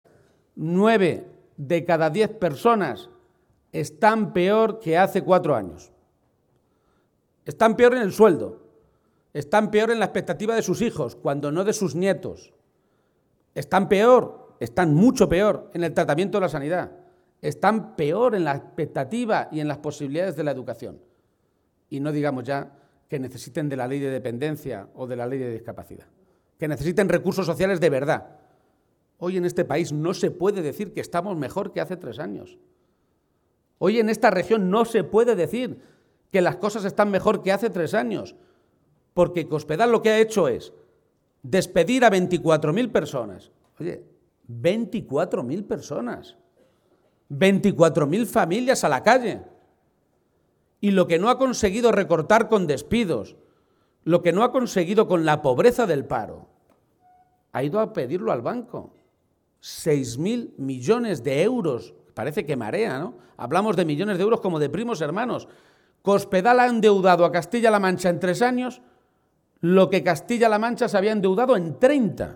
García-Page realizó estas declaraciones tras la tradicional comida navideña que celebraron los socialistas albaceteños y a la que asistieron cerca de 400 militantes y simpatizantes del conjunto de la provincia.
Audio García-Page comida PSOE Albacete-1